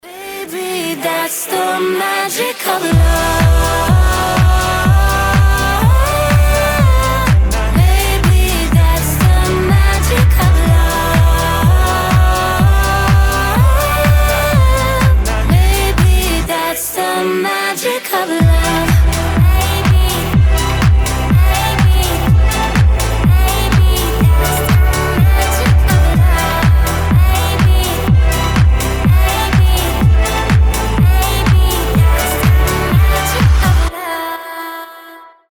• Качество: 320, Stereo
женский голос
Dance Pop